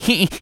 rabbit_squeak_angry_02.wav